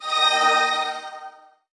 Media:RA_Battle Healer_Evo.wav UI音效 RA 在角色详情页面点击初级、经典和高手形态选项卡触发的音效